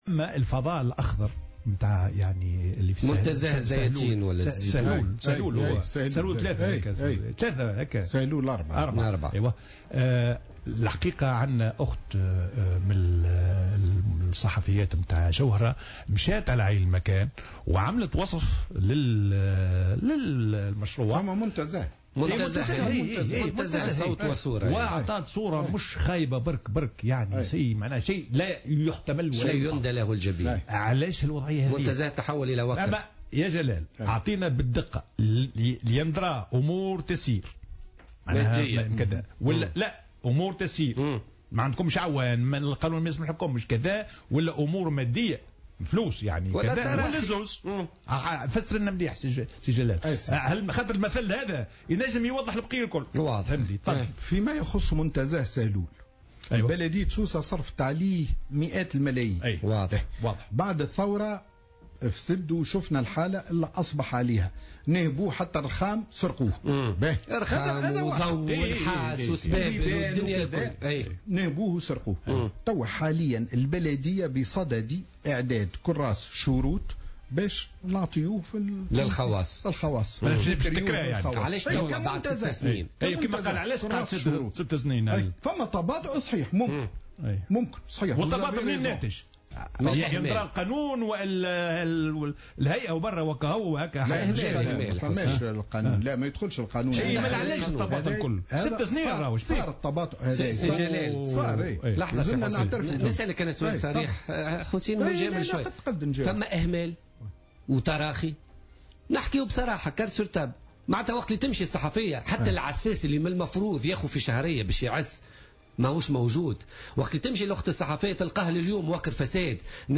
وأضاف تفيفحة ضيف برنامج "بوليتيكا" اليوم أن البلدية أنفقت مئات الملايين على هذا المنتزه، لكنه تعرّض خلال الثورة إلى النهب والتخريب.